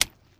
High Quality Footsteps
STEPS Pudle, Walk 01, Single Impact.wav